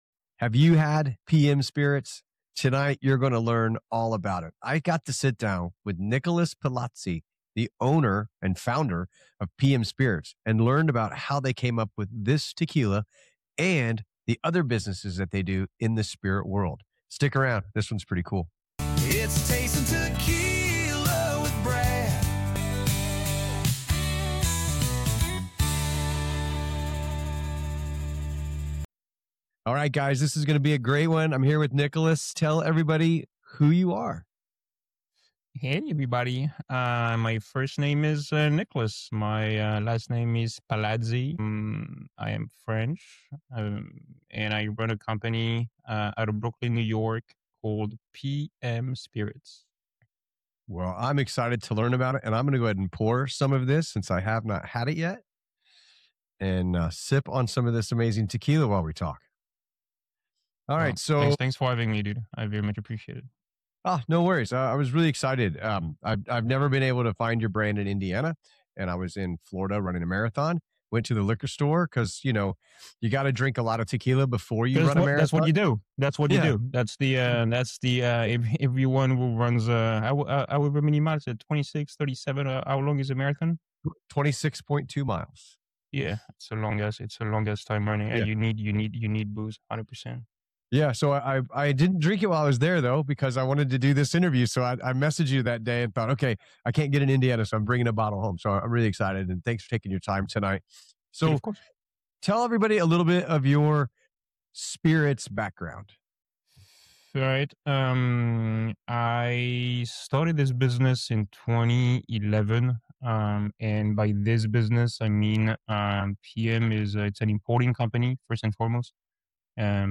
In this interview we talk about his approach to tequila, transparency in production, and why he believes many tequila brands entering the market today will not survive.